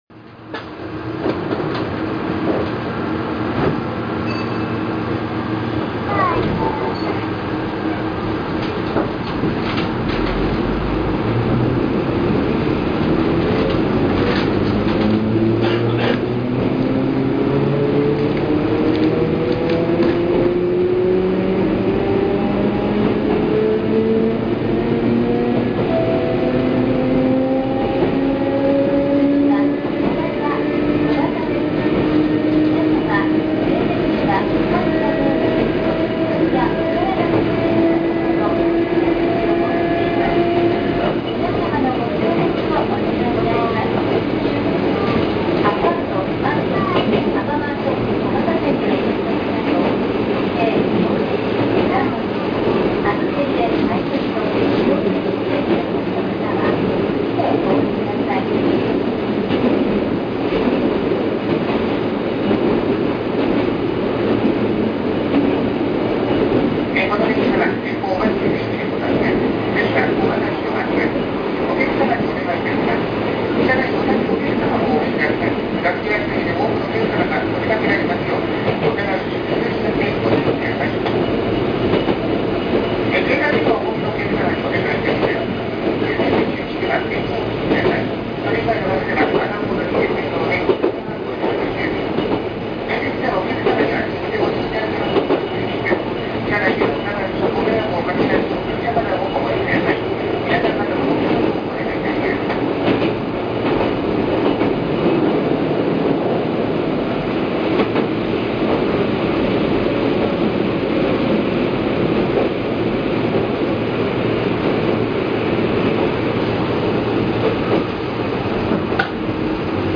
6750系走行音（収録は全て6754Fにて）
【瀬戸線】大曽根〜小幡（5分18秒：2.43MB）
瀬戸線の車両は全て車内自動放送を完備していますが、6750系の車内ではその放送はほとんど聞こえません。